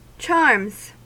Ääntäminen
Ääntäminen US : IPA : [tʃɑɹmz] Tuntematon aksentti: IPA : /tʃɑːɹmz/ Haettu sana löytyi näillä lähdekielillä: englanti Charms on sanan charm monikko.